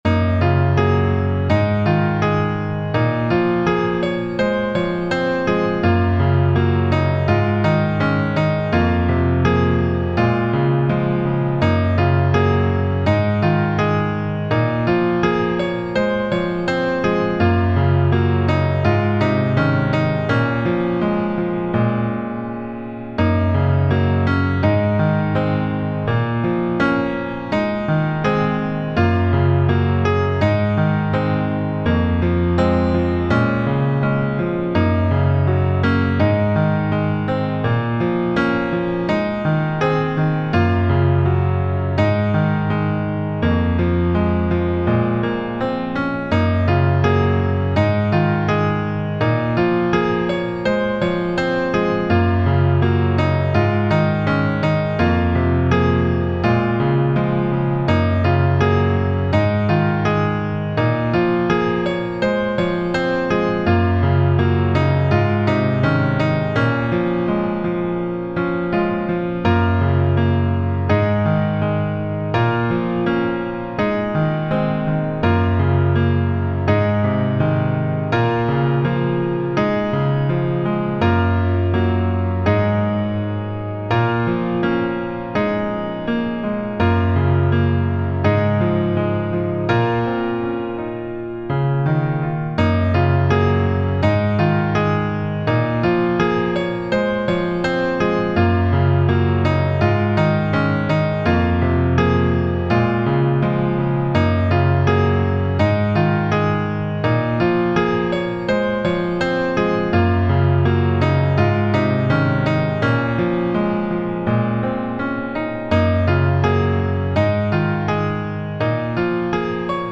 タグ: Chill ほのぼの/穏やか ピアノ リラックス/睡眠 切ない 幻想的 コメント: 切ないソロピアノ楽曲。